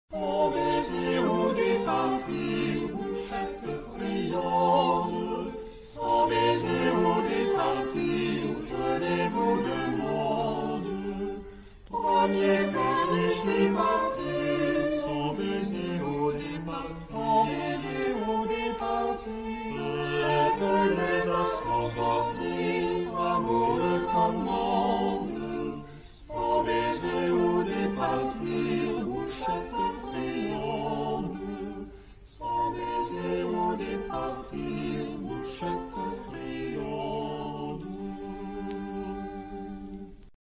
Época : Siglo 16
Género/Estilo/Forma: Renacimiento ; Profano ; Canción
Tipo de formación coral: SATB  (4 voces Coro mixto )
Tonalidad : sol menor